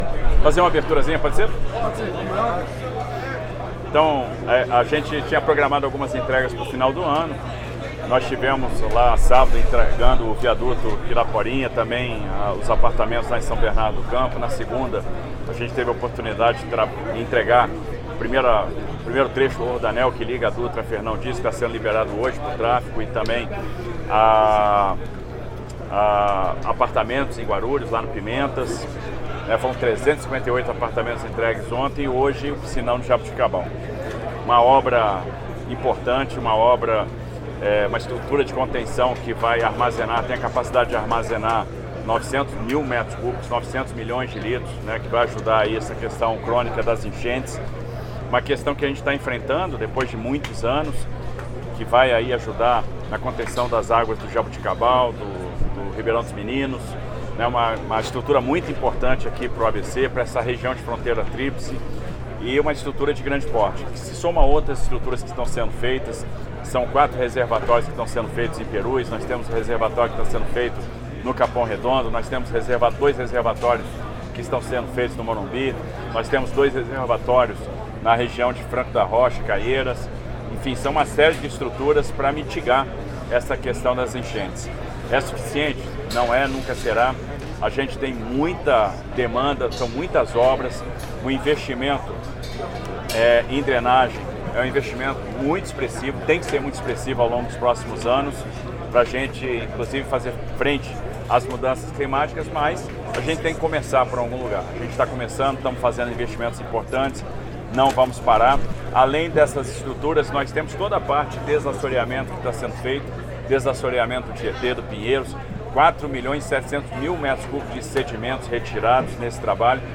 O governador de São Paulo, Tarcísio de Freitas, em agenda em São Bernardo do Campo, no ABC Paulista, nesta terça-feira, 23 de dezembro de 2025, trouxe uma série de informações sobre os transportes metropolitanos, em especial, a linha 6-Laranja de metrô, a concessão para a iniciativa privada para da linha 10-Turquesa da CPTM (Companhia Paulista de Trens Metropolitanos), a linha 14-Ônix e o BRT-ABC.
Todas as declarações ocorreram na entrega das obras do chamado Piscinão Jaboticabal.